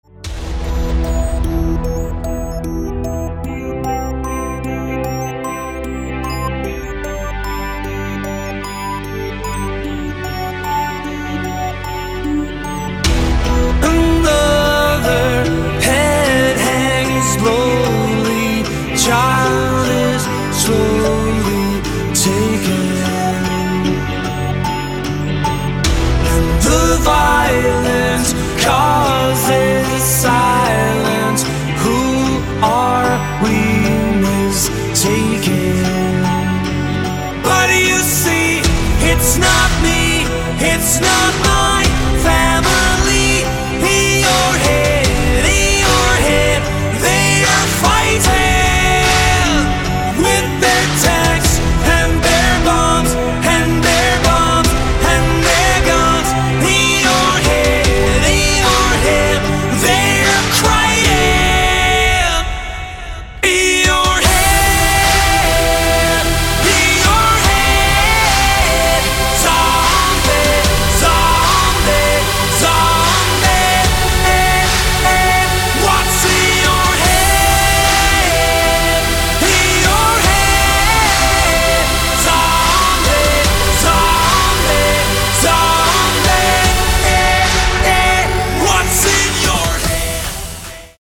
• Качество: 160, Stereo